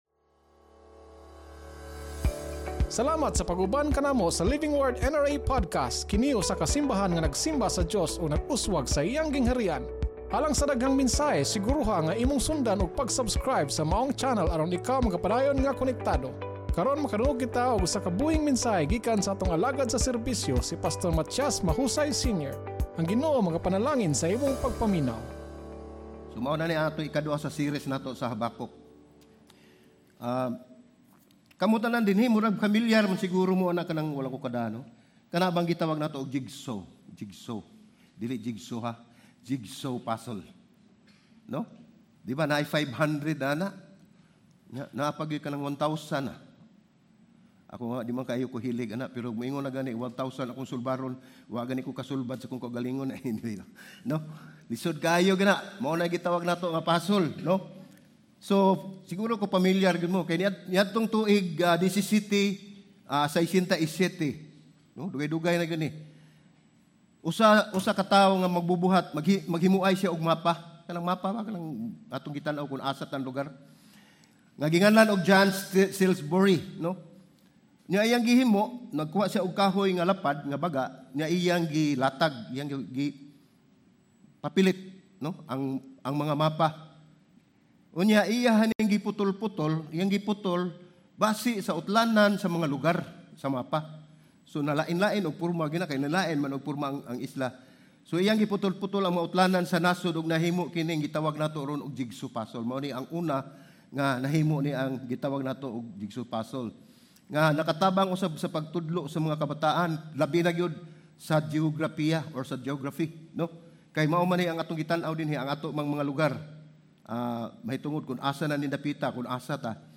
Ang paghulat sa usa ka butang mao ang pag-ila nga wala kitay control. Sermon